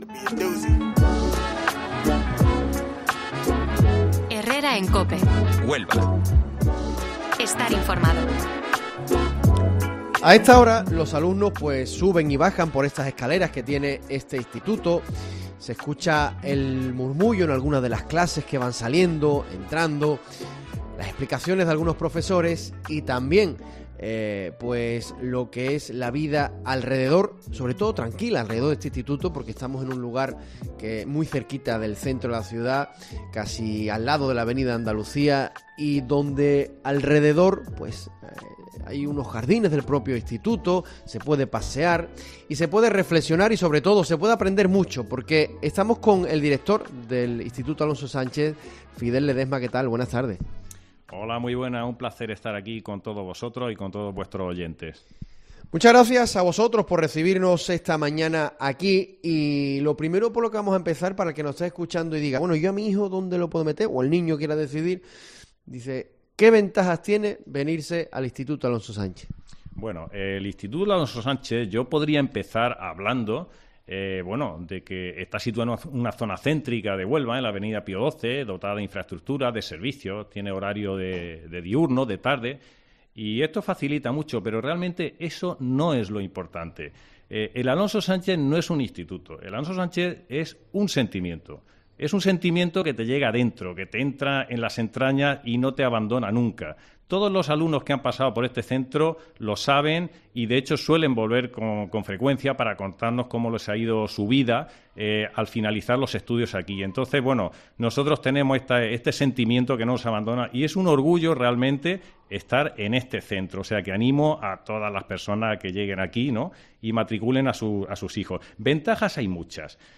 El Herrera en COPE Huelva de este martes 7 de marzo lo realizamos desde el IES Alonso Sánchez, un experimentado centro educativo que atesora 50 años de experiencia.